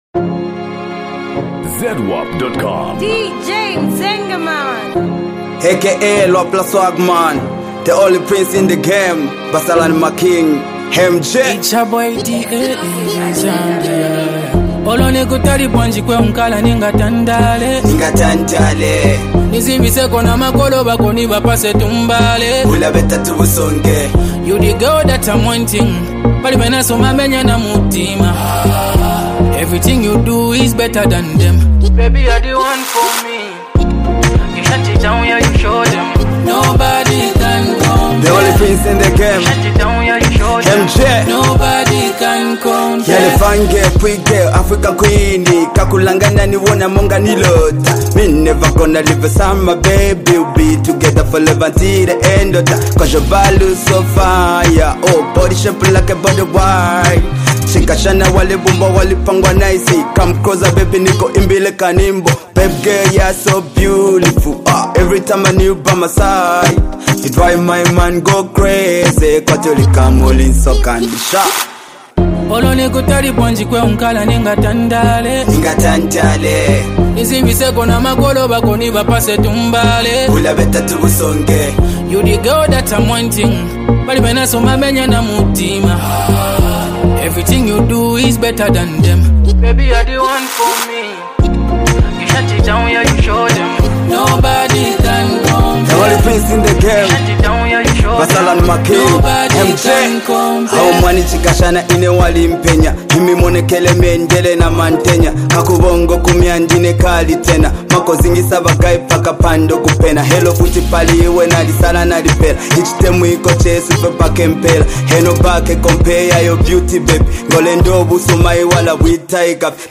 Skillful rapper